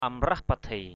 /a-mrah-pa-d̪ʱi:/ (t.) lả lơi = inconvenant. flirting, inappropriate.